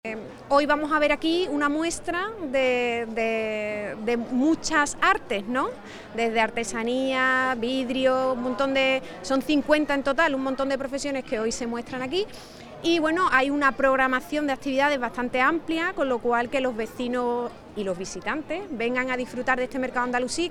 El diputado provincial Ignacio Trujillo y la teniente de alcalde delegada de Desarrollo Económico y Fomento de Empleo, Turismo y Comercio, Beatriz Gandullo, han participado en el acto oficial de apertura de la vigésimocuarta edición del Mercado Andalusí, que organizan en colaboración la Institución Ferial de la Provincia, IFECA, dependiente de la Diputación y el Ayuntamiento de Cádiz.